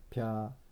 0229 ぴぁ゜/ぴぁ ぴ・あ・まる/ぴ・あ ぴぁ゜/ぴぁ（竹富方言） pyä